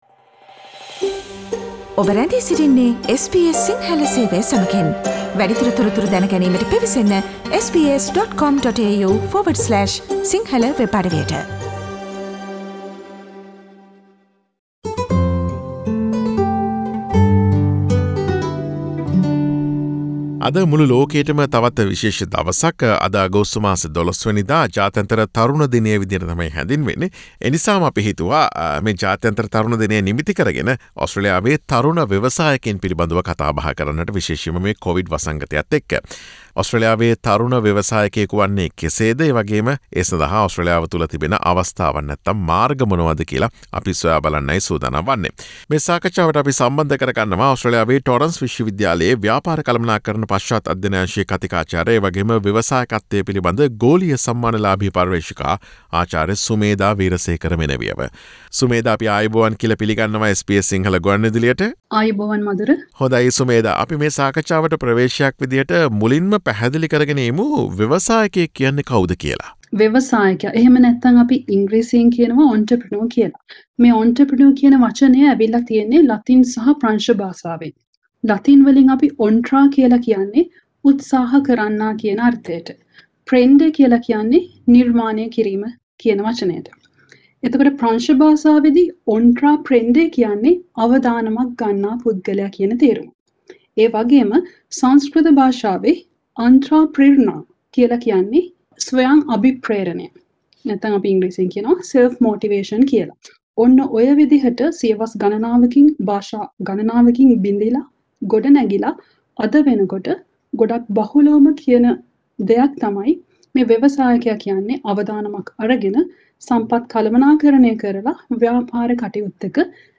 අගෝස්තු 12 ට යෙදී ඇති ජාත්‍යන්තර තරුණ දිනය නිමිතිකොටගෙන මෙම කොවිඩ් වසංගත කාලයේදී ඔස්ට්‍රේලියාවේ තරුණ ව්‍යවසායකයෙකු වන්නේ කෙසේද සහ ඒ සඳහා ඇති අවස්ථාවන් මොනවාද යන්න පිළිබඳ SBS සිංහල ගුවන් විදුලිය සිදුකළ සාකච්ඡාවට සවන් දෙන්න.